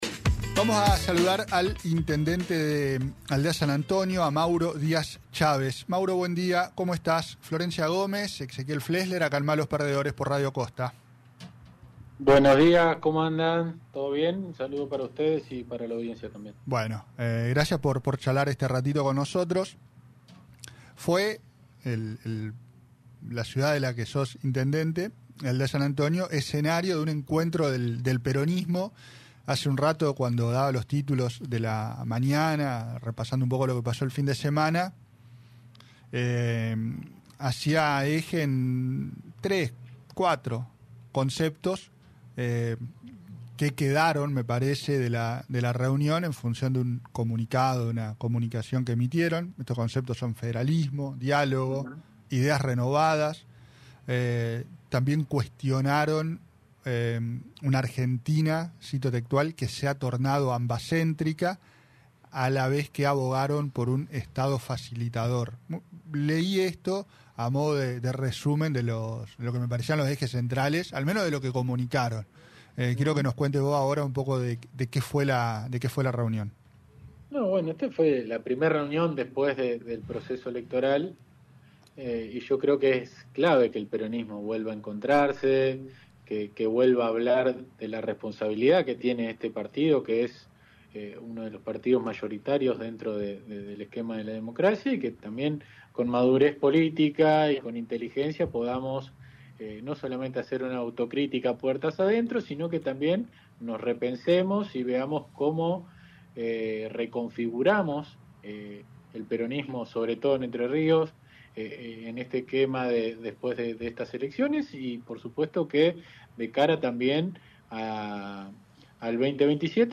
El intendente de Aldea San Antonio, Mauro Díaz Chávez, anfitrión del encuentro de dirigentes peronistas realizado el sábado, habló de la necesidad del partido de aggiornarse. Entrevista en Malos Perdedores.